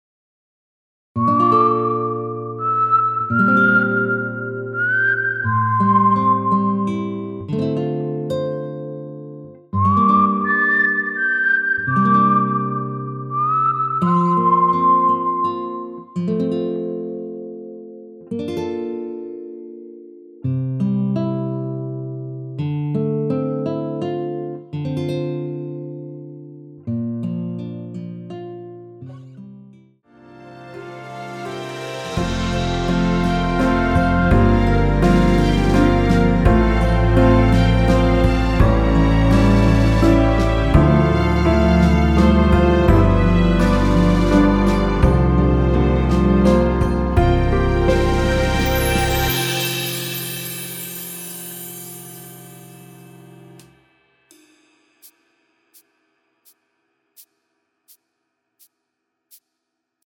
무반주 구간 들어가는 부분과 박자 맞출수 있게 쉐이커로 박자 넣어 놓았습니다.(미리듣기 확인)
원키에서(-3)내린 MR입니다.
앞부분30초, 뒷부분30초씩 편집해서 올려 드리고 있습니다.
중간에 음이 끈어지고 다시 나오는 이유는